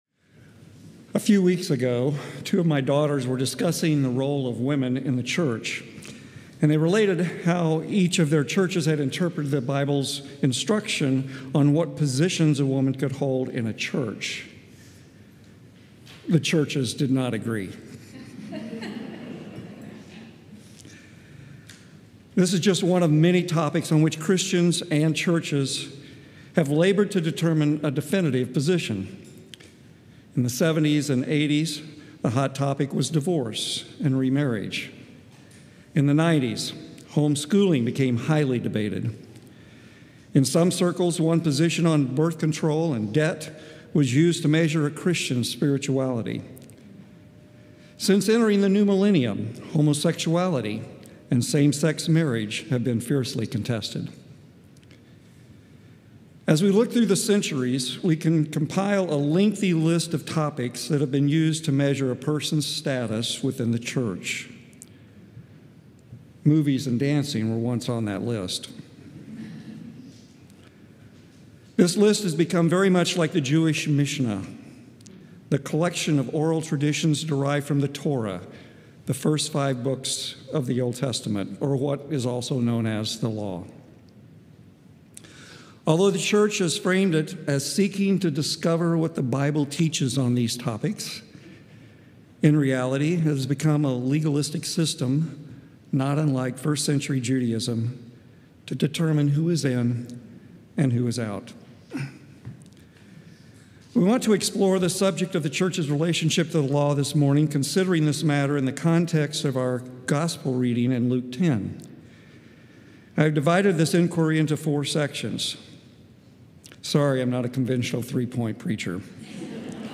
COR Sermon – July 13, 2025
Sunday Sermon